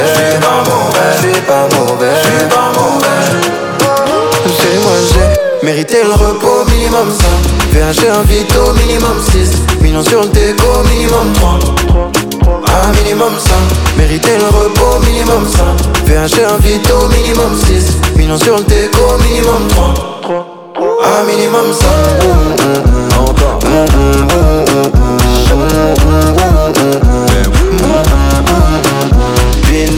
Жанр: Африканская музыка